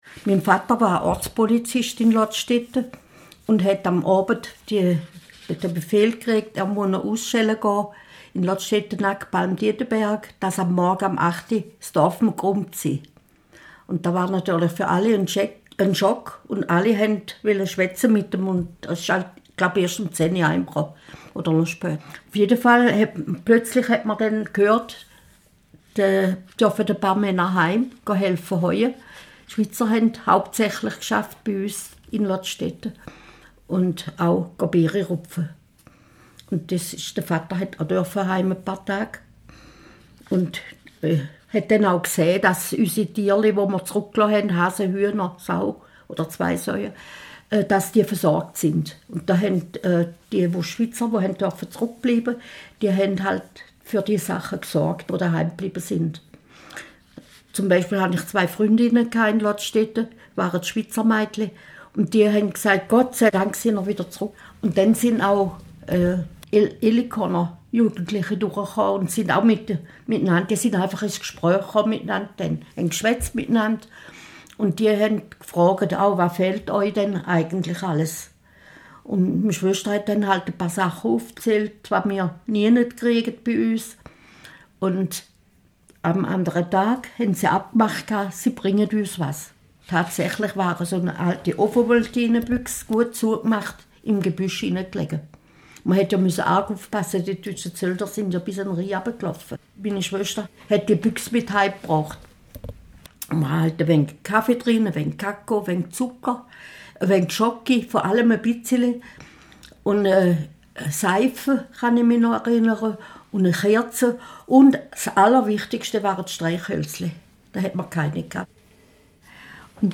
Dazu sollen Zeitzeugen, die mit den Gebäuden in Verbindung stehen, zu Wort kommen. In Tonaufnahmen mit Hilfe der Methode «Oral History» sollen diese Menschen zu ausgewählten Bauwerken erzählen und ihre persönlichen Erinnerungen und Erlebnisse teilen.